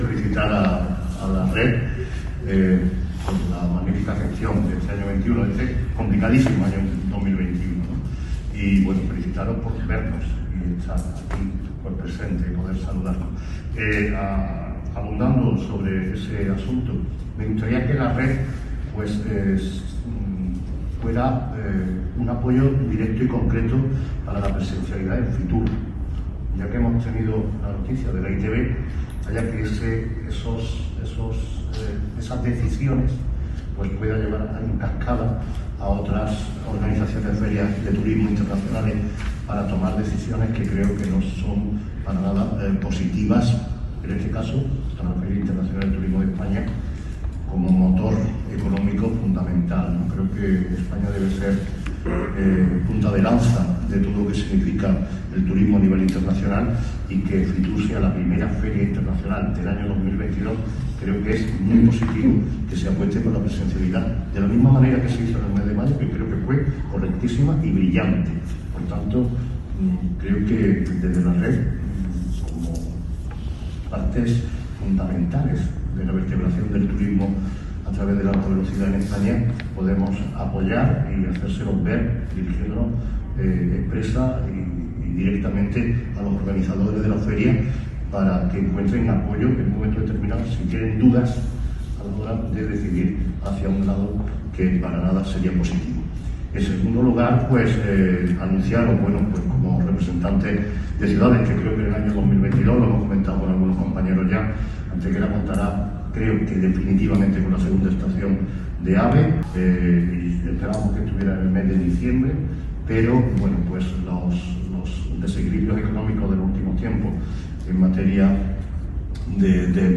El Alcalde de Antequera participa en Sevilla en una nueva asamblea de la Red de Ciudades AVE
Cortes de voz